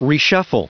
Prononciation du mot reshuffle en anglais (fichier audio)
Prononciation du mot : reshuffle